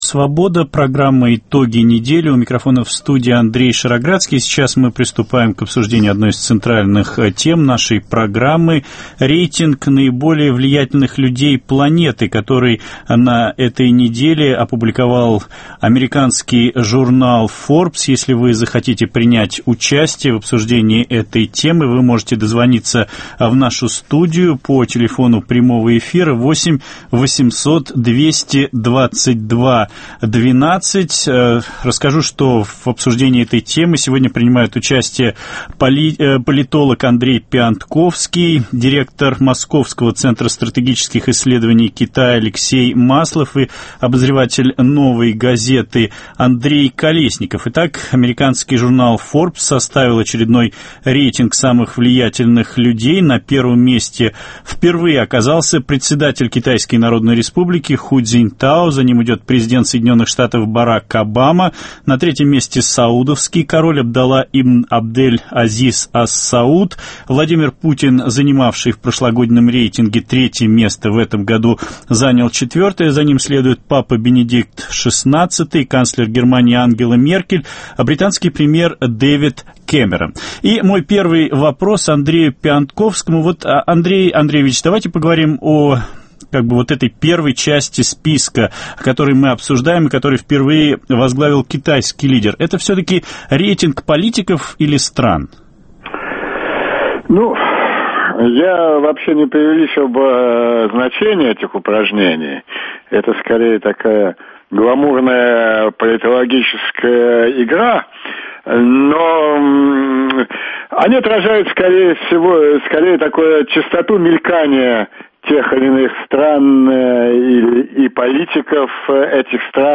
Беседа с экспертами о том, почему журнал Форбс назвал самым влиятельным человеком в мире Председателя КНР Ху Цзиньтао, обзоры еженедельников и блогов, Гламорама, реплика Виктора Шендеровича